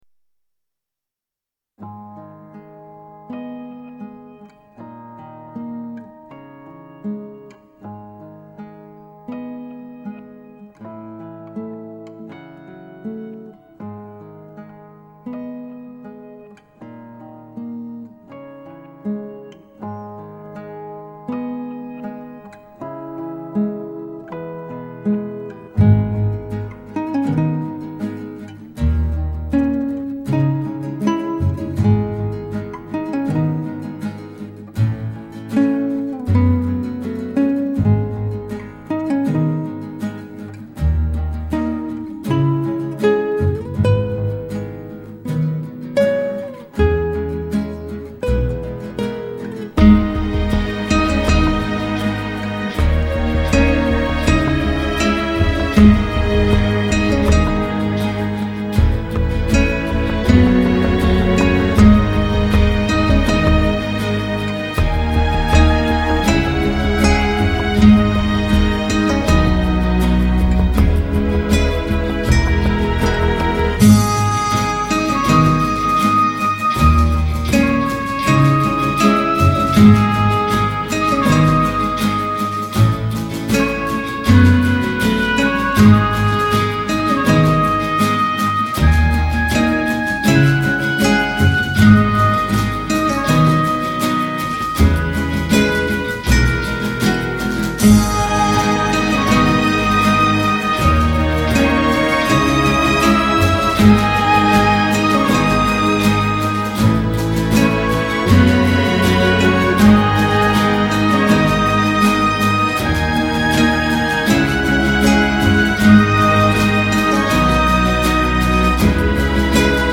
Western